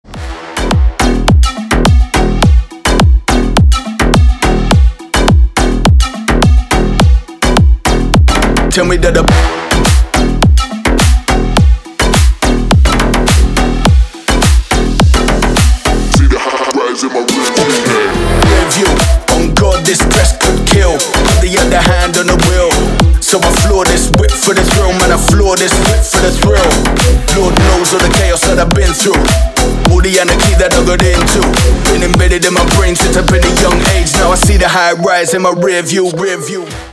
• Качество: Хорошее
• Песня: Рингтон, нарезка